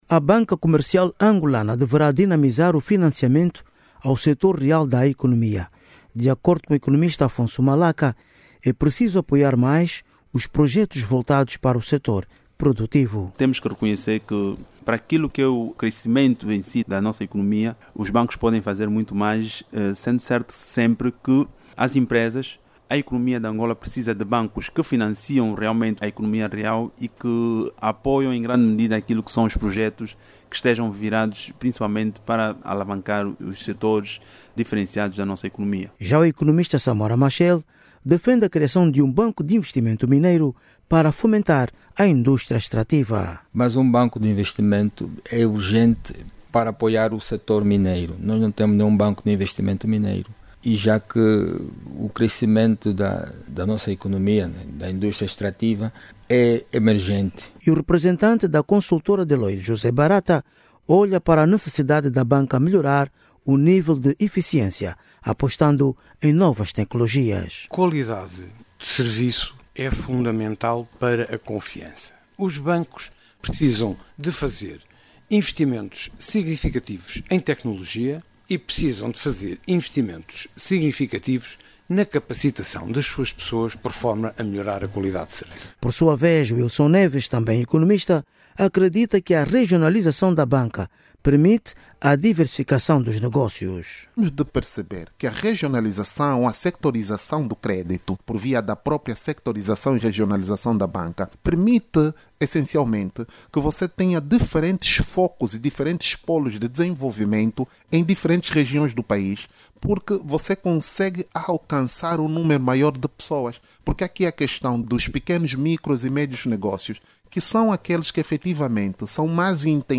Economistas angolanos, continuam a defender um maior empenho da banca comercial, com vista a dinamização o sector real da economia. Falando no programa Azimute Actualidade, os especialistas em números, olham para a necessidade das autoridades apostarem em serviços que fomentem a banca de investimento.